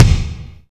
• Shiny Kick Sample D Key 71.wav
Royality free kick single hit tuned to the D note. Loudest frequency: 763Hz
shiny-kick-sample-d-key-71-fIR.wav